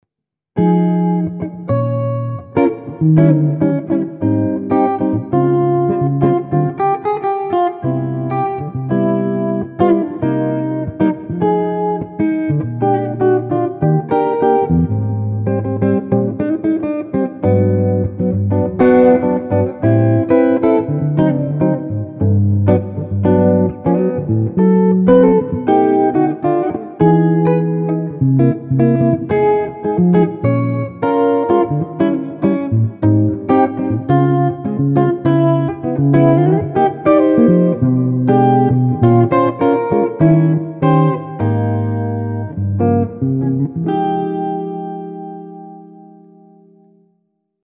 ... avrei volentieri messo il naso in una tua personale versione chord melody.
ATTYA_Chord Melody.mp3